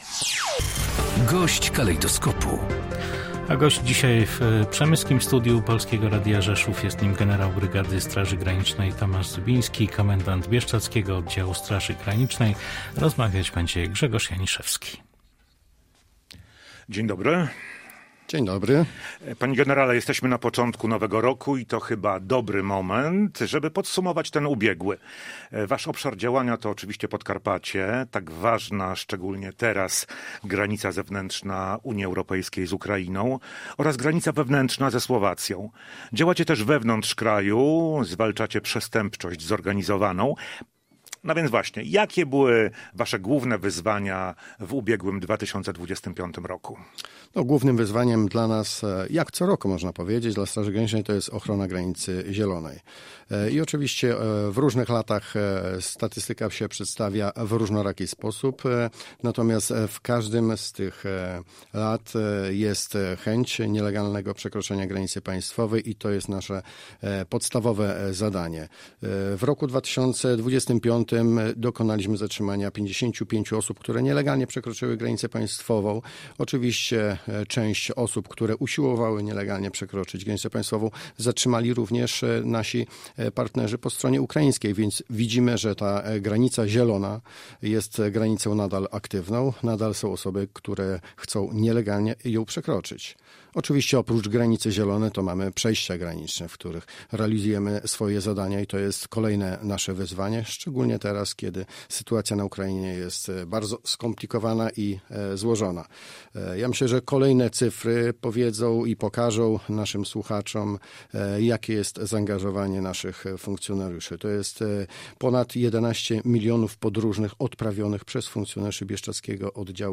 Generał brygady Tomasz Zybiński podsumował w Polskim Radiu Rzeszów 2025 rok.